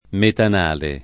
[ metan # le ]